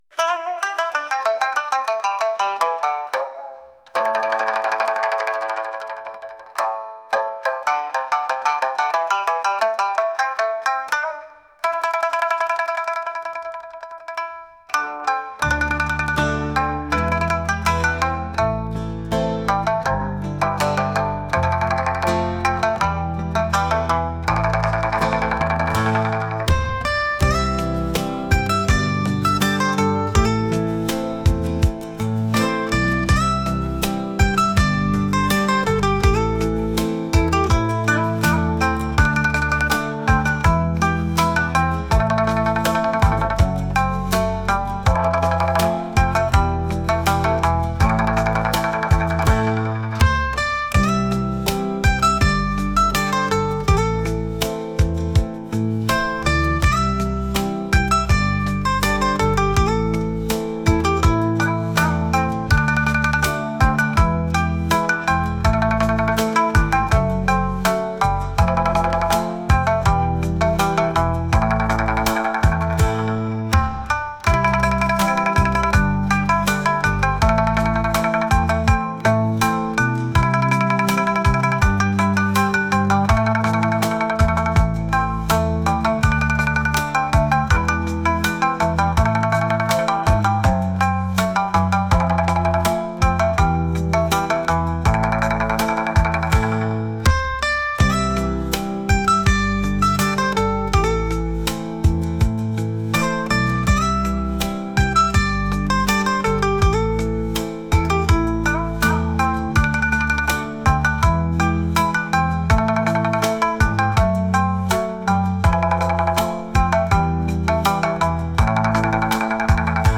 三味線を使ってのんびりしたような曲です。